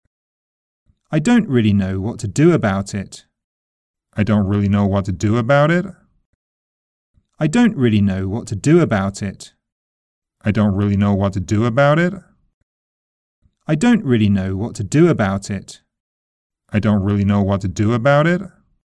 イギリス英語は下げ口調が多く、文中の一番ストレスする言葉から高いリズムで始まり下げていくというものです。一方アメリカ英語は上げ口調が多く、一番ストレスする言葉からリズムが始まり上げていくというものです。
（1番目がイギリス英語、2番目がアメリカ英語で3回流れます。）
american-british-intonation-i-dont-really-know-３回.mp3